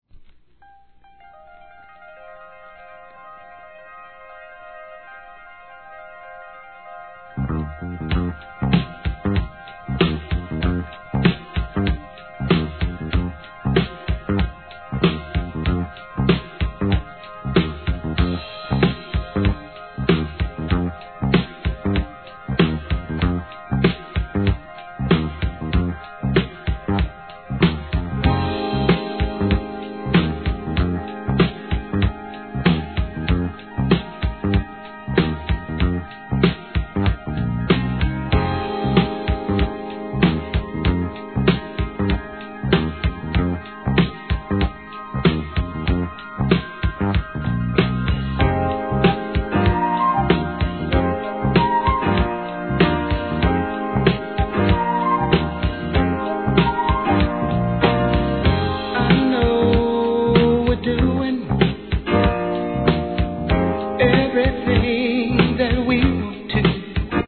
1. SOUL/FUNK/etc...